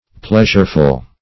Pleasureful \Pleas"ure*ful\, a.